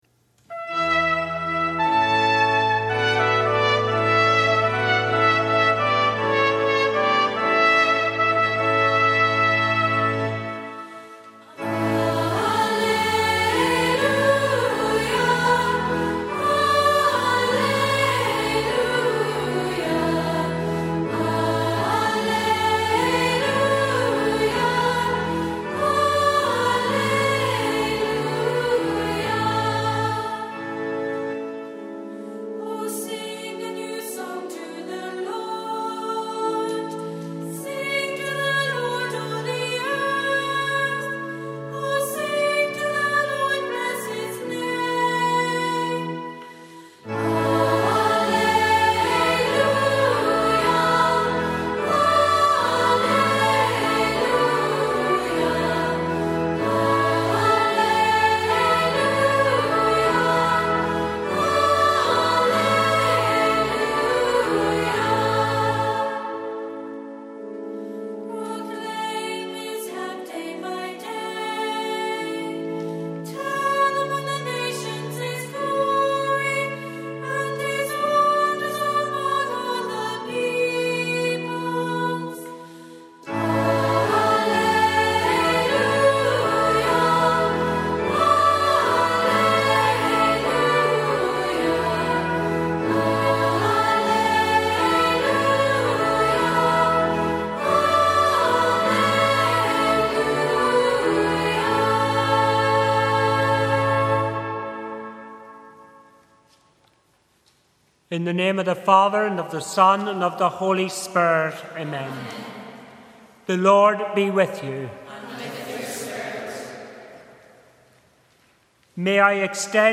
BBC Radio Ulster broadcasted its weekly Morning Service from St Joseph’s Church, Meigh.
The broadcast featured our award-winning Choir